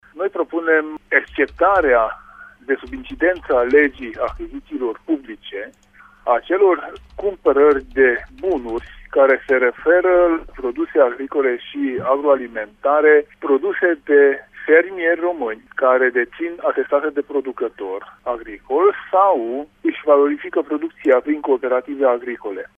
Senatorul UDMR Tanczos Barna a declarat că formațiunea a depus la Senat o iniţiativă legislativă care propune ca fermierii să fie scutiţi de procedura de achiziţie publică: